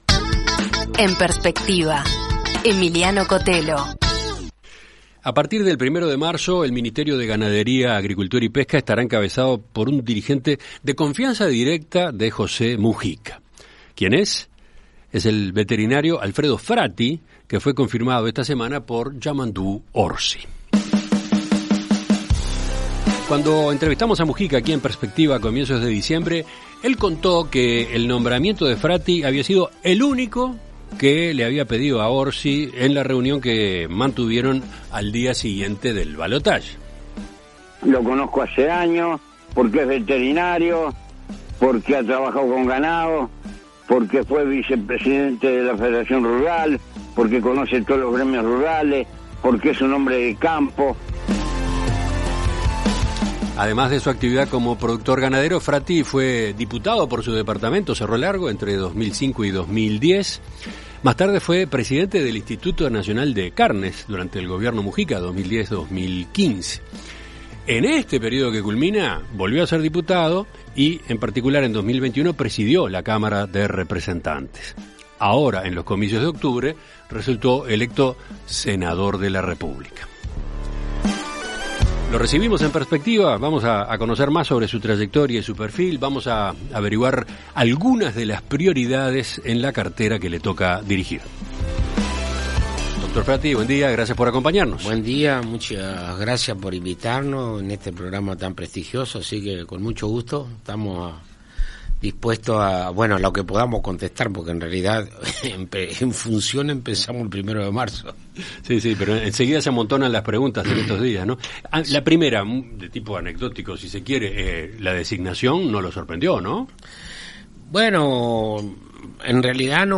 En Perspectiva Zona 1 – Entrevista Central: Alfredo Fratti - Océano